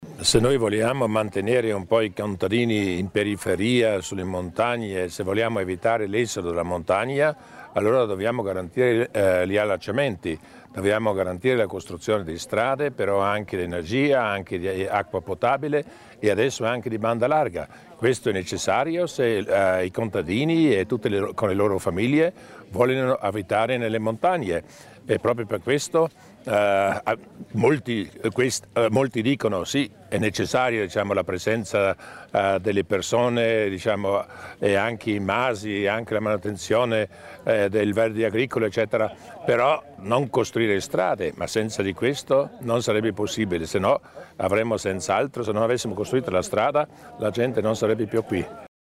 Il Presidente Durnwalder spiega l'importanza della nuova strada per i Masi della Muta
La nuova strada di accesso ai Masi della Muta è stata aperta ufficialmente oggi, venerdì 11 ottobre 2013, alla presenza del presidente della Provincia.